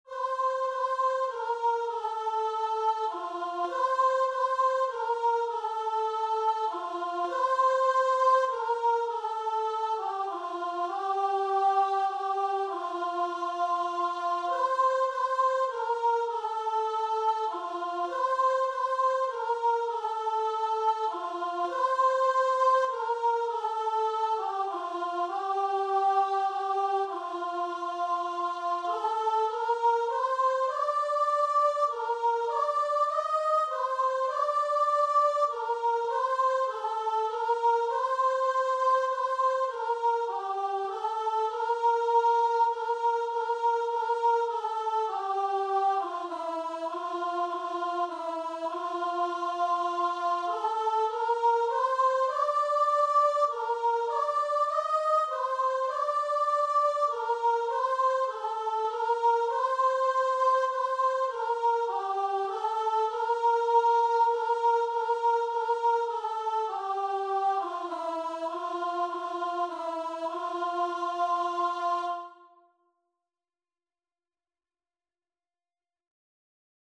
Christian Christian Guitar and Vocal Sheet Music In Thee Gladness
Free Sheet music for Guitar and Vocal
3/4 (View more 3/4 Music)
F major (Sounding Pitch) (View more F major Music for Guitar and Vocal )
Guitar and Vocal  (View more Easy Guitar and Vocal Music)
Classical (View more Classical Guitar and Vocal Music)